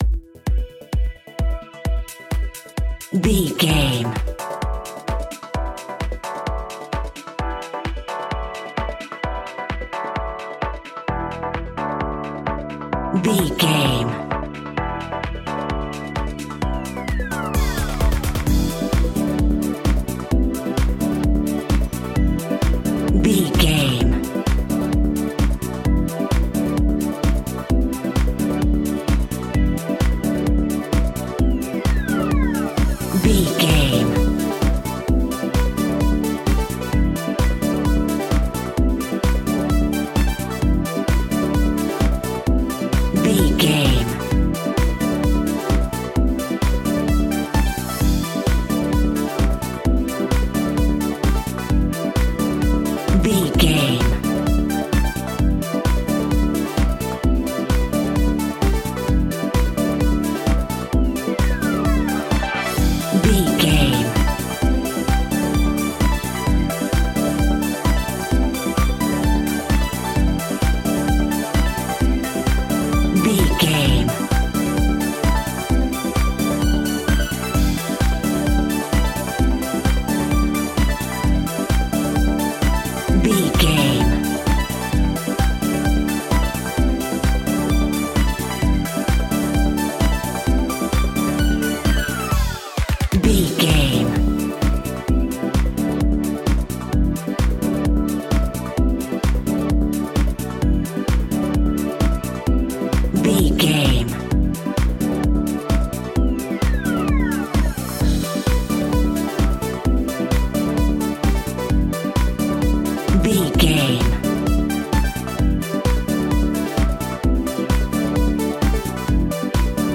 Aeolian/Minor
groovy
uplifting
driving
energetic
drums
bass guitar
electric guitar
synthesiser
strings
electric piano
funky house
nu disco
upbeat